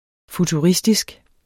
Udtale [ futuˈʁisdisg ]